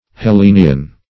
Hellenian \Hel*le"ni*an\